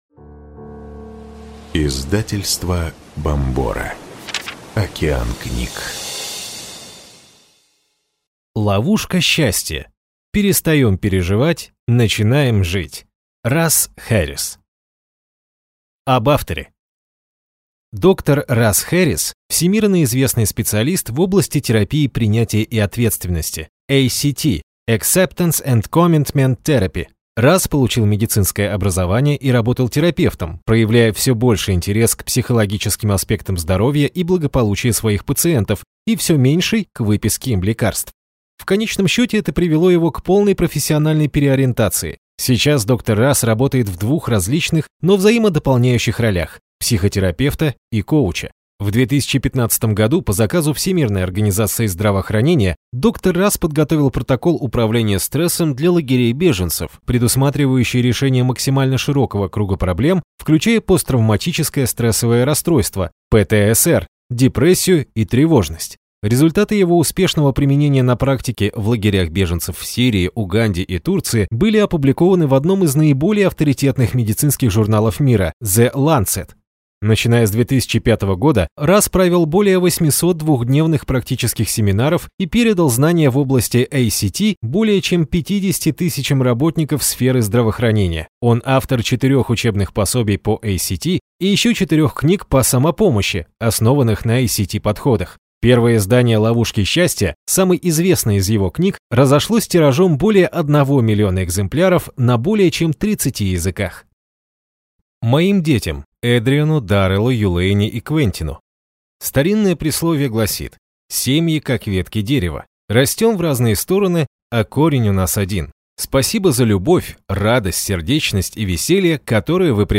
Аудиокнига Ловушка счастья. Перестаем переживать – начинаем жить | Библиотека аудиокниг